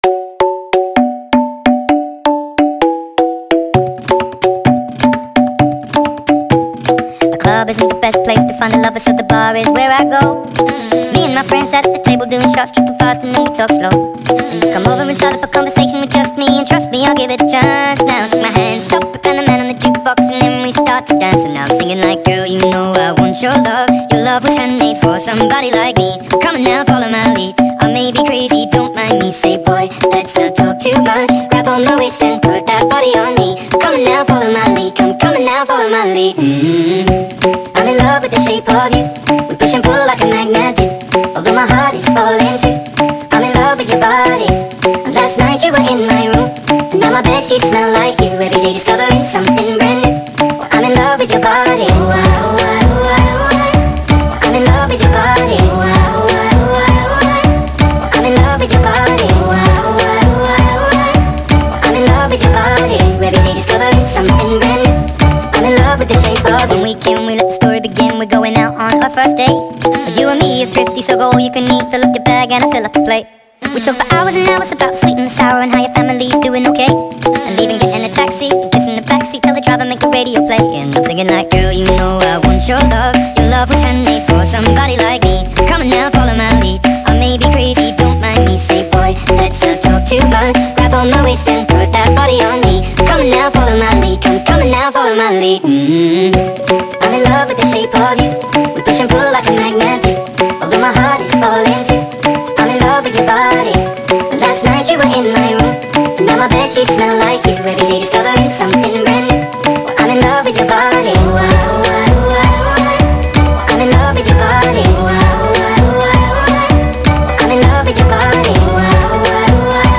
با ریتمی سریع شده
شاد
عاشقانه